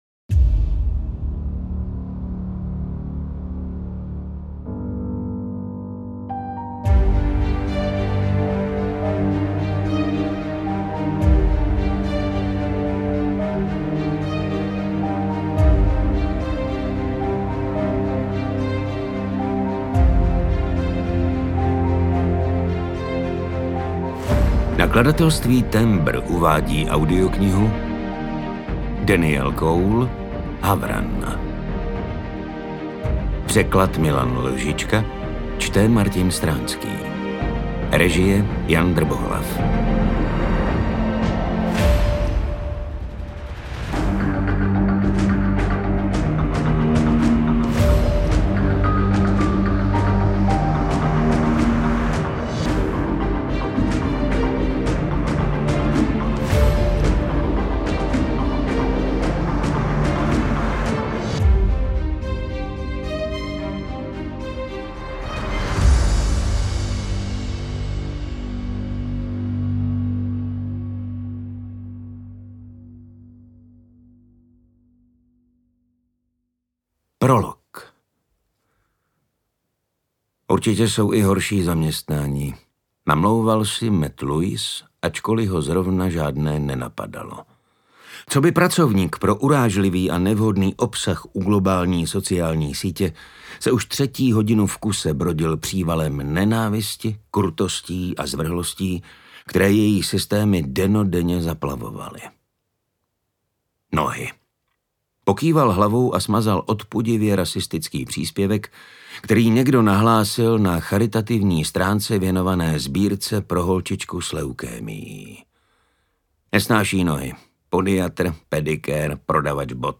UKÁZKA Z KNIHY
Čte: Martin Stránský
audiokniha_havran_ukazka.mp3